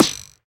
hitForth_Close.wav